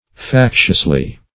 factiously - definition of factiously - synonyms, pronunciation, spelling from Free Dictionary
-- Fac"tious*ly, adv.